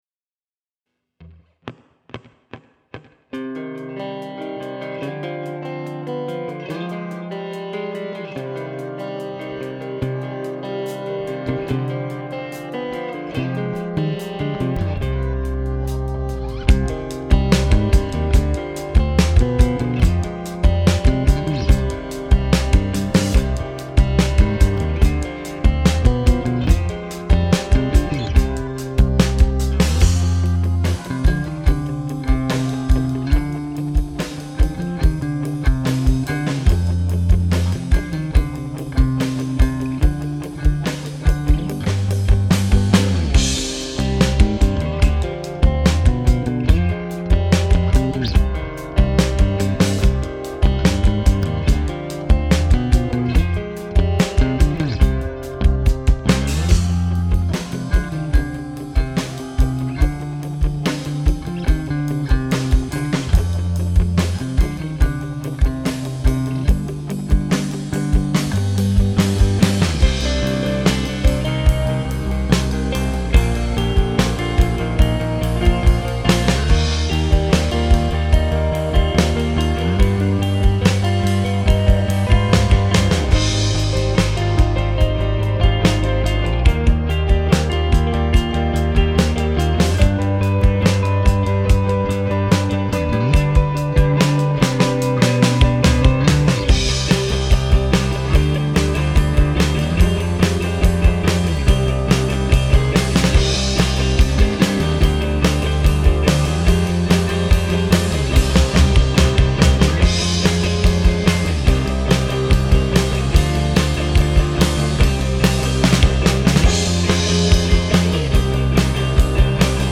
Here’s a fun instrumental I made a few days ago, right after our cat, Pixie, ambushed a fox on our front porch.